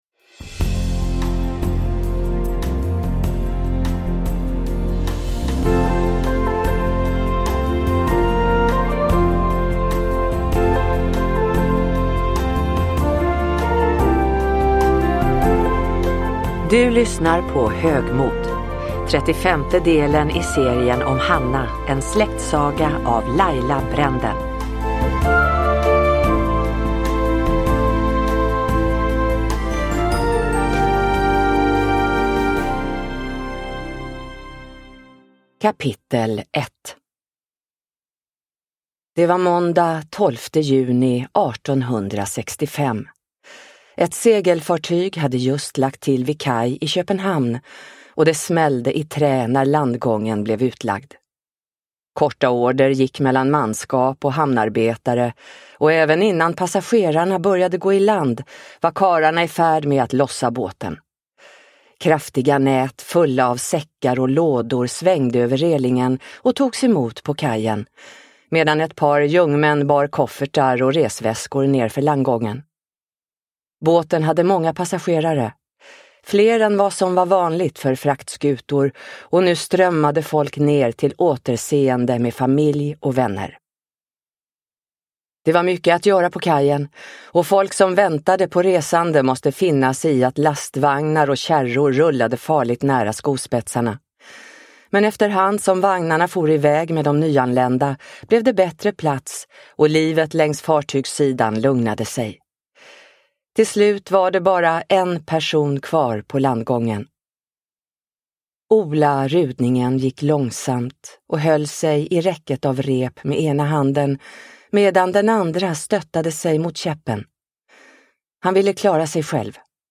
Högmod – Ljudbok – Laddas ner